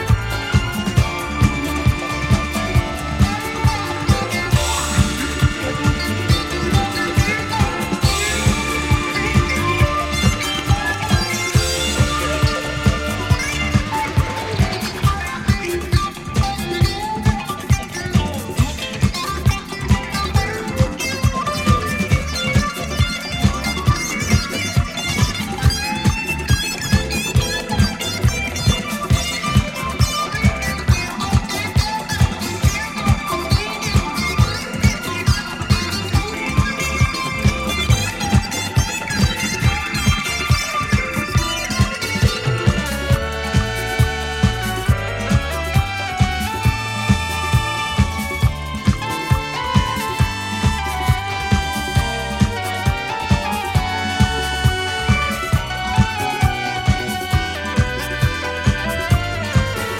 メロディアスな爽やかナンバーを収録
ジャンル(スタイル) DISCO / FUNK / SOUL